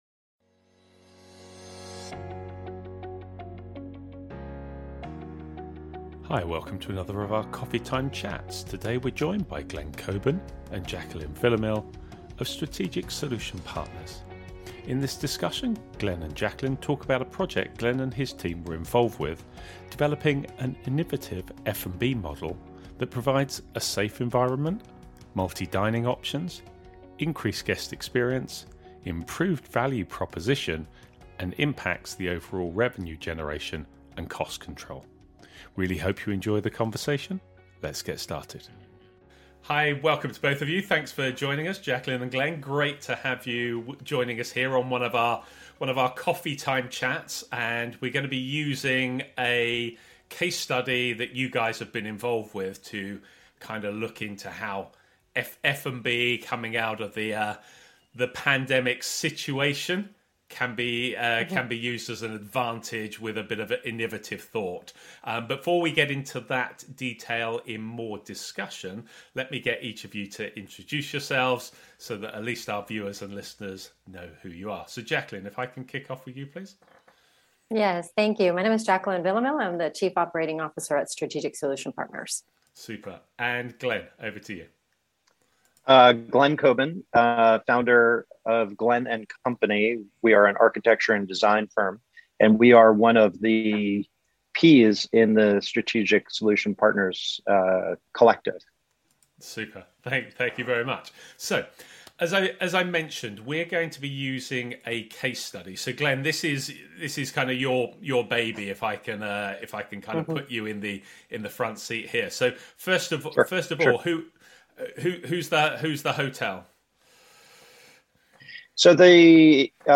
Welcome to another of our Coffee Time chats.